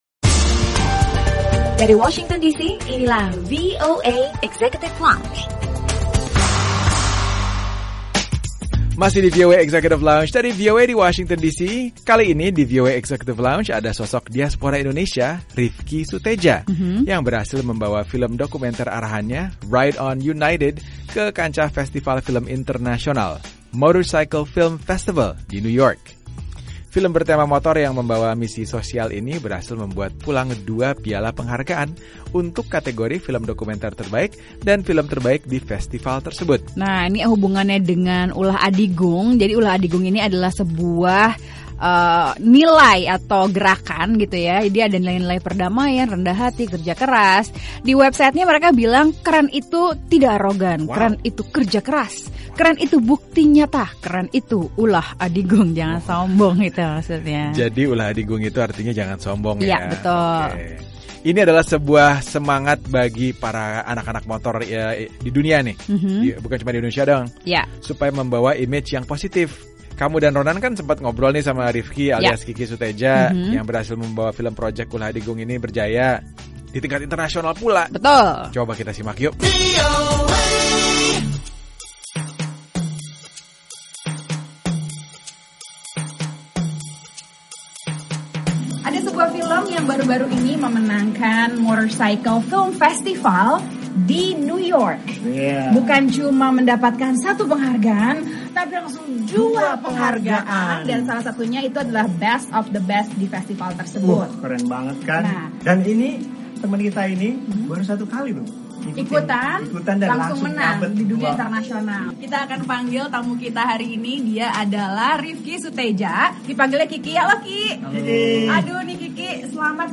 Obrolan